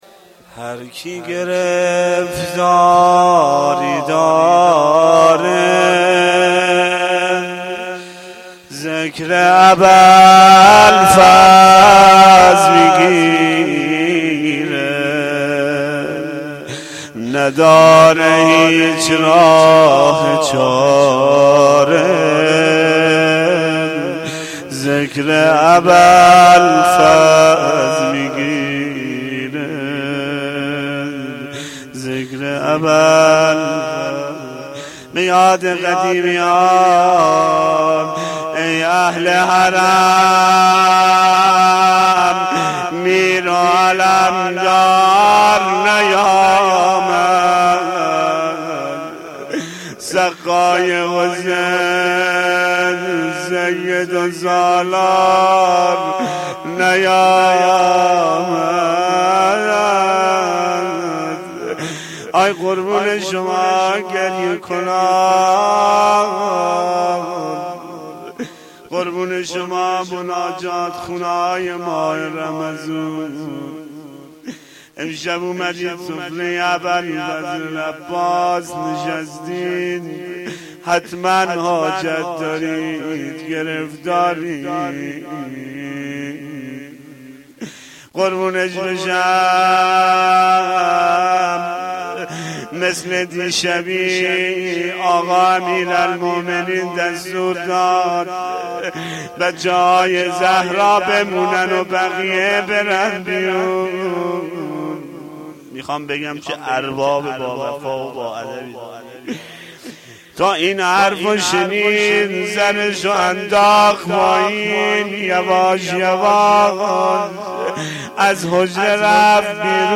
روضه
roze-Rozatol-Abbas-Ramezan93-sh4.mp3